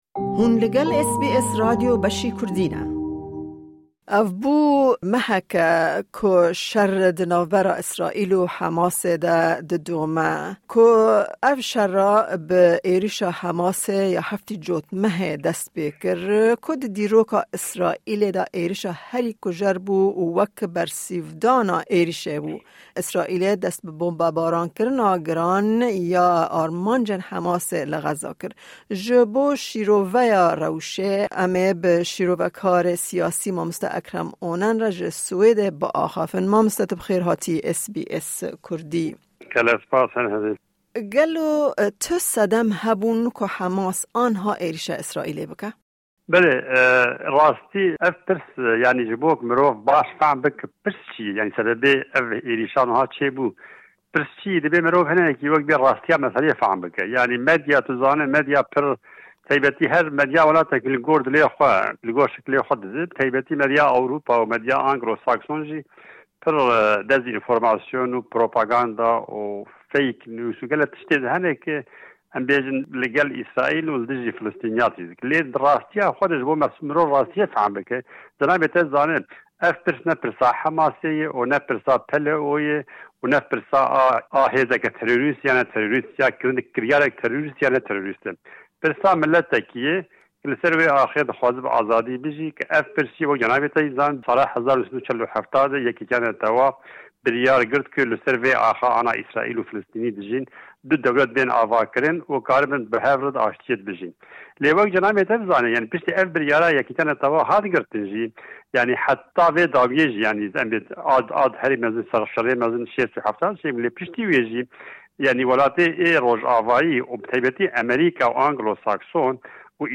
political analyst Credit